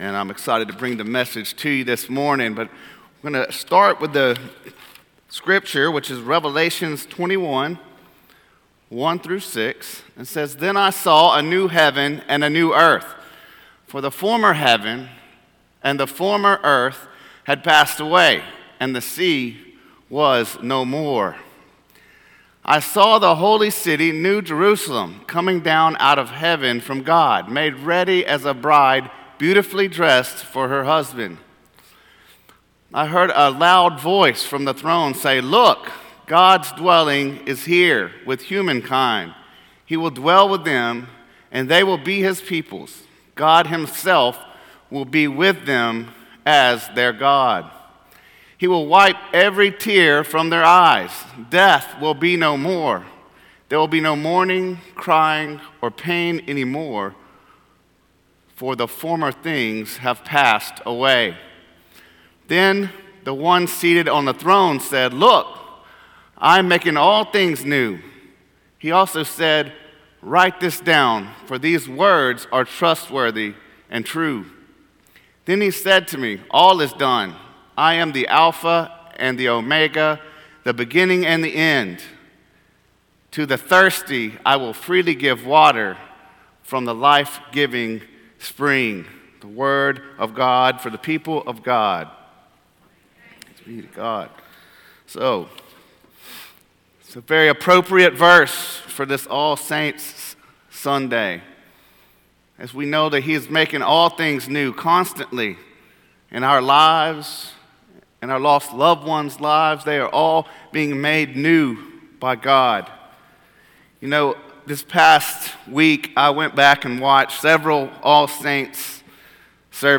Sermons - Cokesbury Church